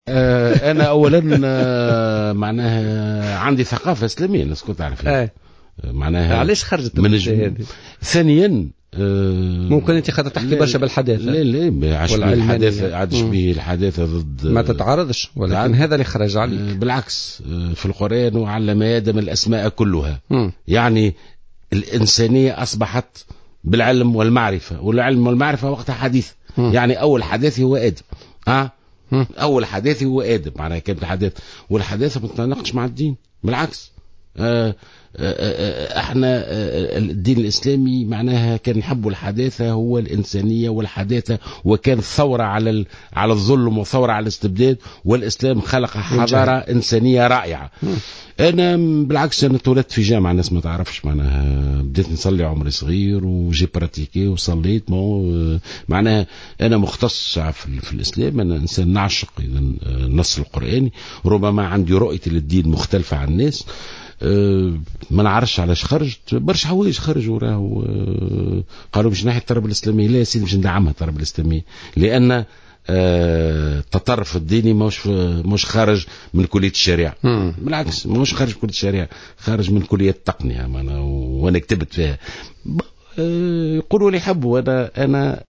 قال وزير التربية والتعليم ناجي جلول اليوم الاثنين 18 ماي 2015 في برنامج "بوليتيكا" على "جوهرة أف أم" إنه سيدعم مادة التربية الاسلامية ضمن المناهج التعليمية في تونس.